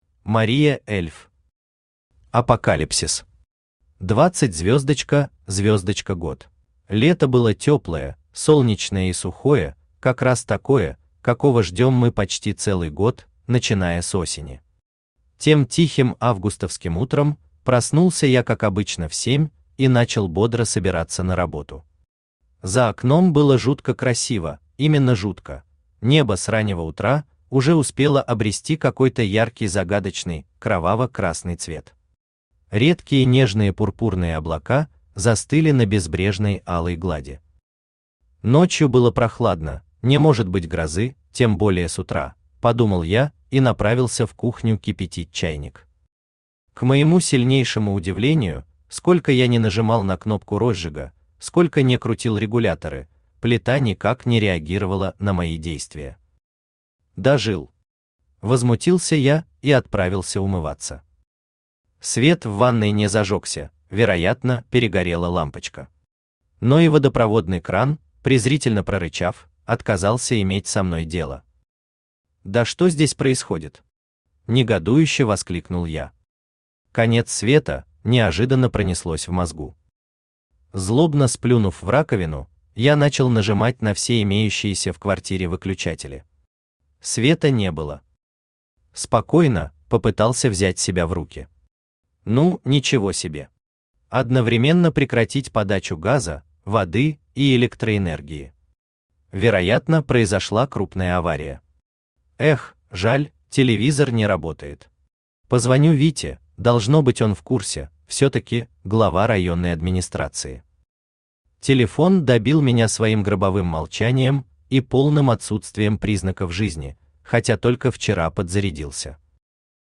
Aудиокнига Апокалипсис. 20**год Автор Мария Эльф Читает аудиокнигу Авточтец ЛитРес.